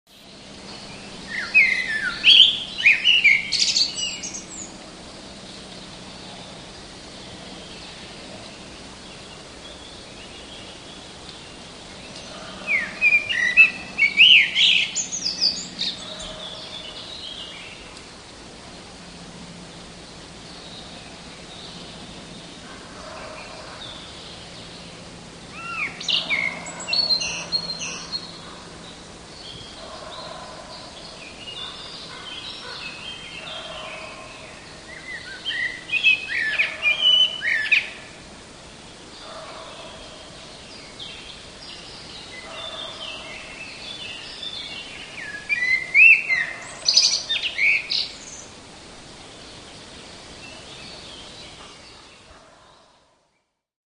nagraniu warto zwrócić uwagę na końcówkę trzeciej piosenki, ponieważ kos naśladuje tam głos śpiewającej bogatki.